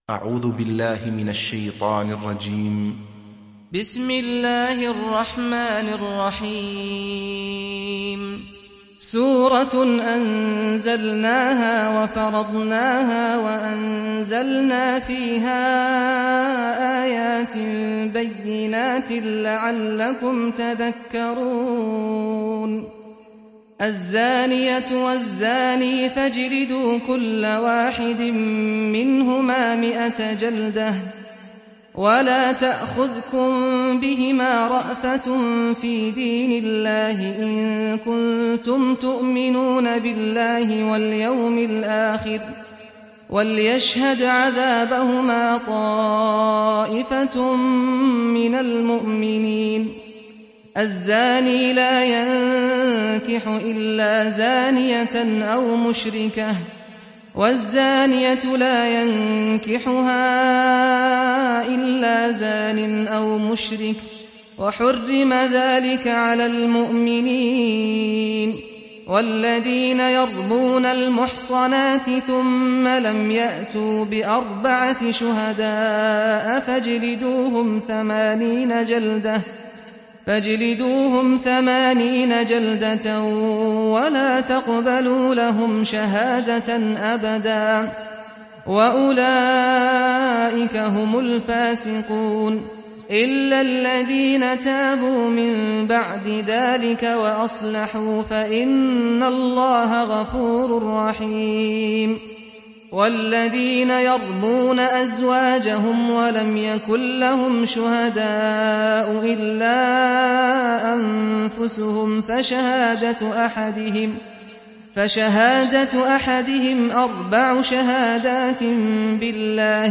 Surat An Nur mp3 Download Saad Al-Ghamdi (Riwayat Hafs)
Surat An Nur Download mp3 Saad Al-Ghamdi Riwayat Hafs dari Asim, Download Quran dan mendengarkan mp3 tautan langsung penuh